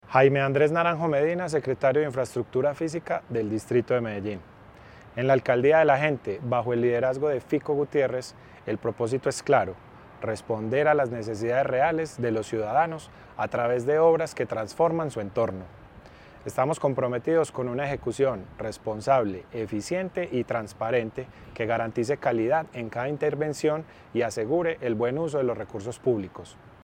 Audio Declaraciones del secretario de Infraestructura Física, Jaime Andrés Naranjo Medina
Audio-Declaraciones-del-secretario-de-Infraestructura-Fisica-Jaime-Andres-Naranjo-Medina-2.mp3